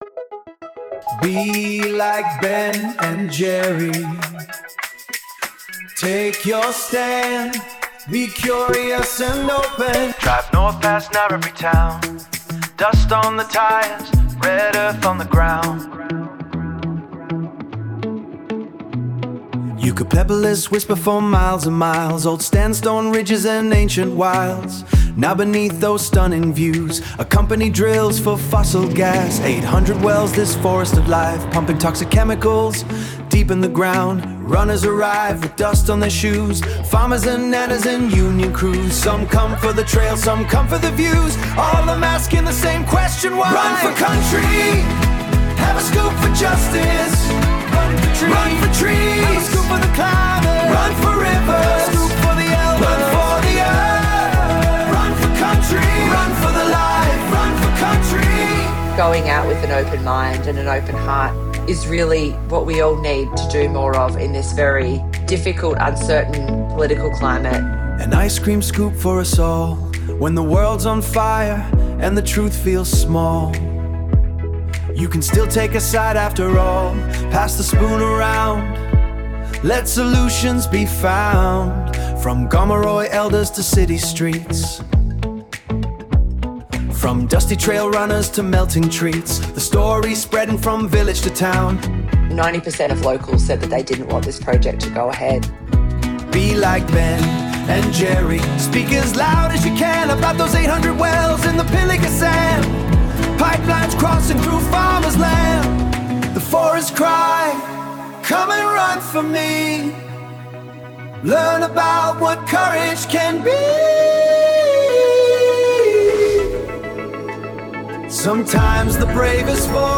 New song